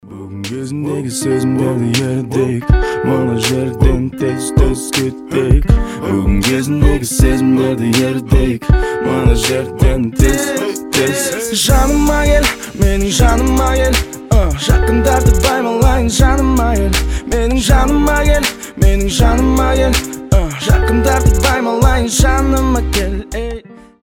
Хип-хоп
RnB